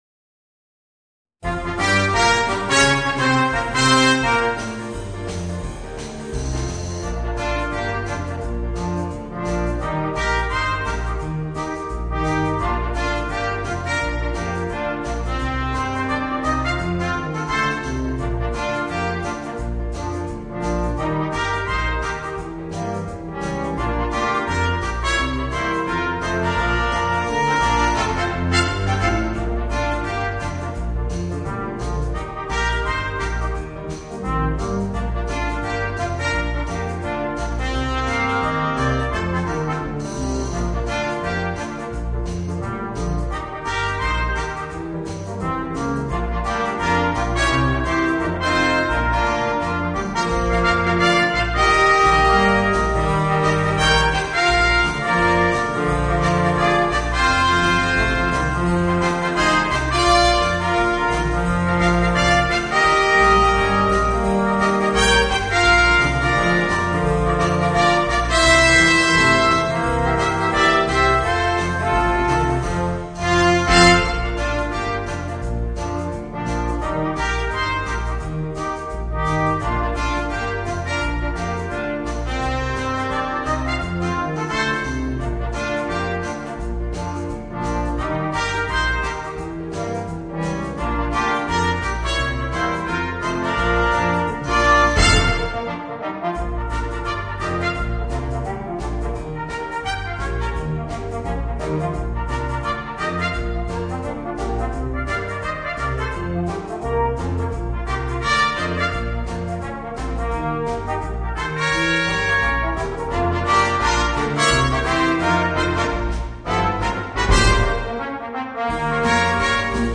Voicing: 2 Trumpets, 3 Trombones and Drums